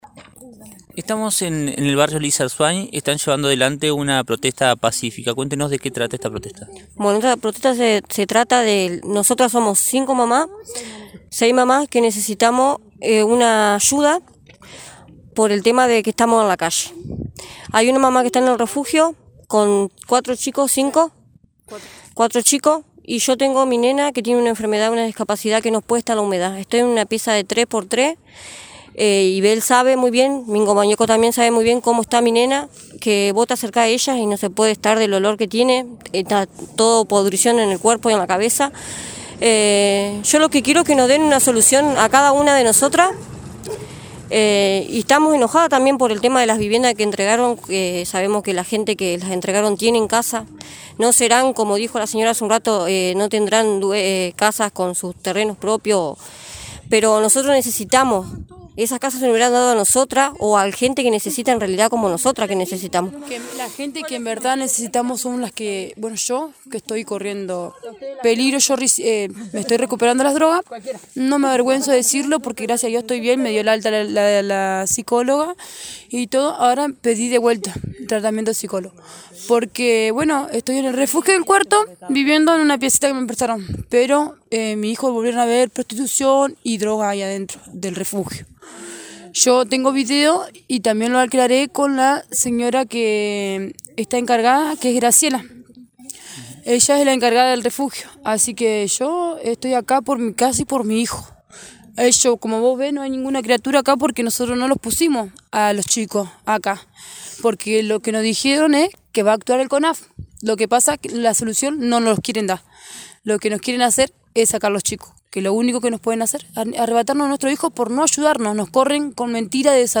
En diálogo con lt39 denunciaron prostitución y drogas en el refugio del Cuarto Cuartel, y amenazan con usurpar las viviendas del barrio.